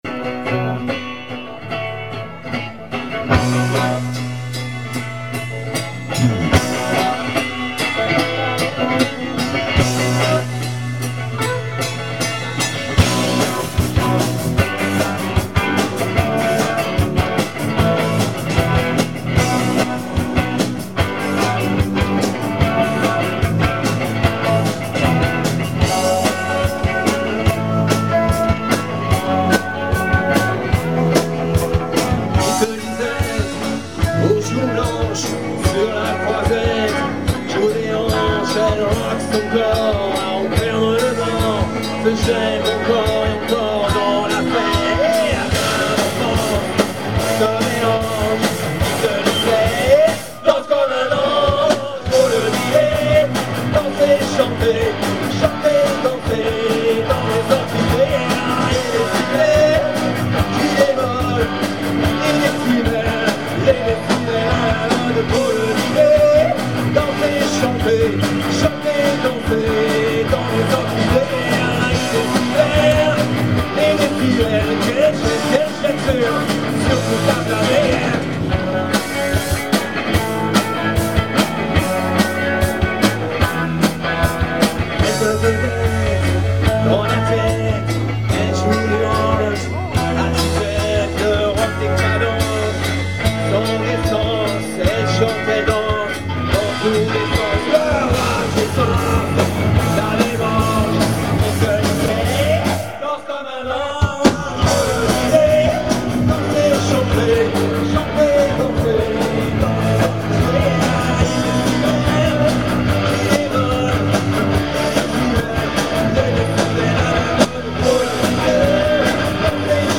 Deux morceaux enregistrés AVEC LES MOYENS DU BORD pendant le live à l'ALTERNAT: (son très médiocre, mais essayez d'aller au-delà)
ensuite, un gros défaut mageur, c'est le batteur, on sent qu'il a du mal à rester régulier niveau tempo, des parties ralentissent ou accélèrent (c'est vraiment audible).
y'a effectivement un probleme d'accordage bass-guit
moi sur le sparoles ce que j'aime pas trop, c'est le contenu des paroles. la mélodie des paroles, moi ca me gène pas trop, je trouve que ca rajoute un coté bluesy.
danser_dans_les_orchidees_live.mp3